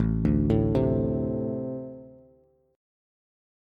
Ambb5 Chord
Listen to Ambb5 strummed